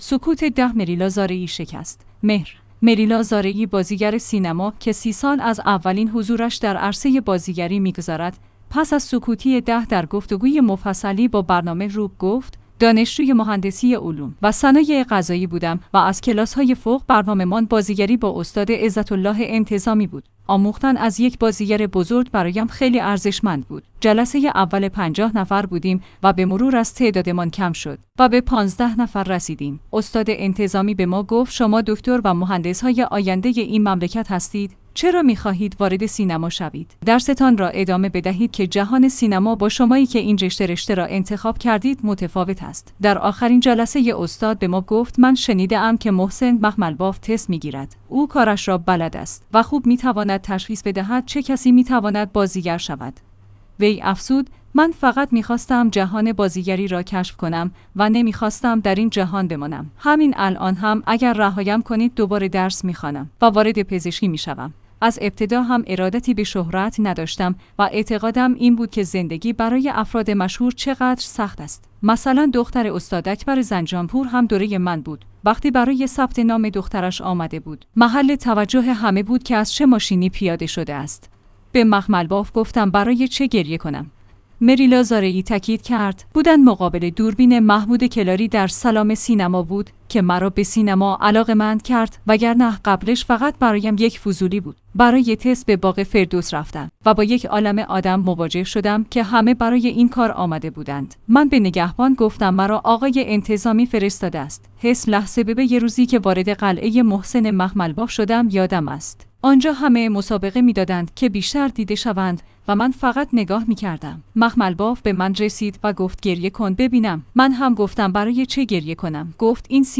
مهر/ مریلا زارعی بازیگر سینما که ۳۰ سال از اولین حضورش در عرصه بازیگری می گذرد، پس از سکوتی ۱۰ ساله در گفتگوی مفصلی با برنامه «رو ب رو» گفت: دانشجوی مهندسی علوم و صنایع غذایی بودم و از کلاس‌های فوق برنامه‌مان بازیگری با استاد عزت الله انتظامی بود. آموختن از یک بازیگر بزرگ برایم خیلی ارزشمند بود.